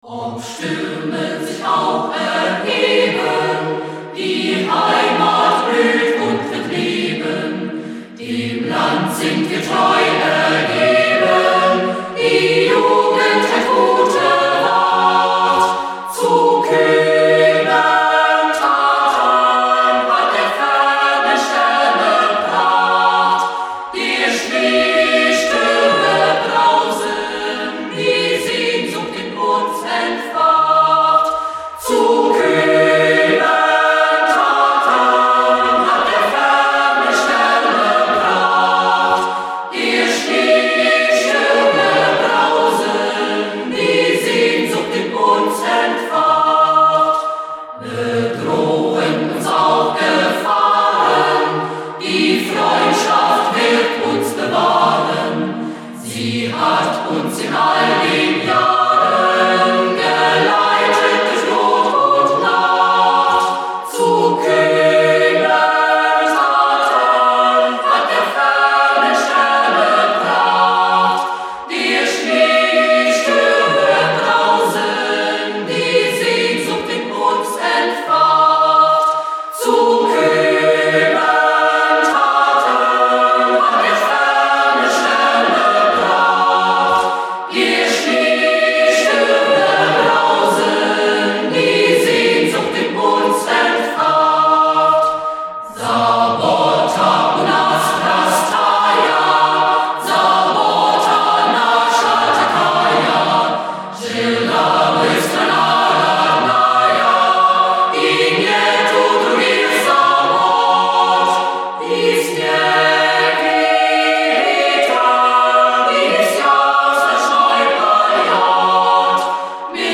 По-моему, это детский хор.
Приятный хор.